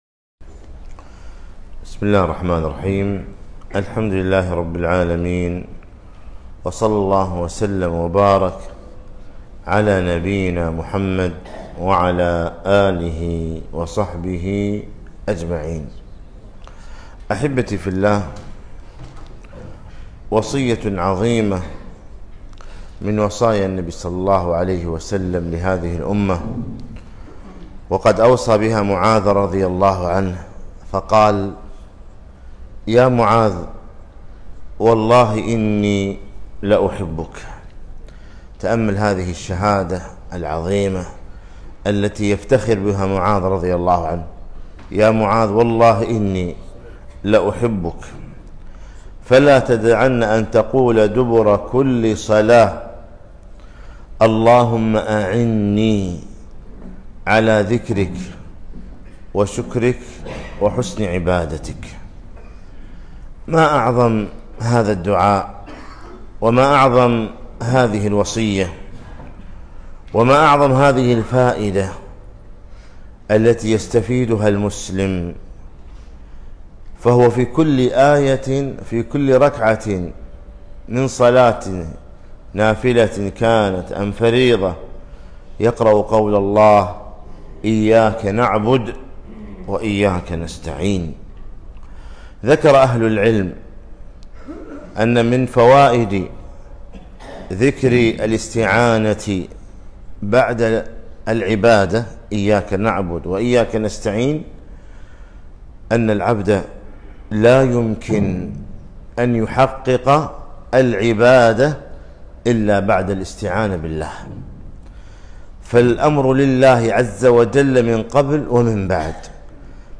كلمة - وصية النبي صلى الله عليه وسلم لمعاذ بن جبل رضي الله عنه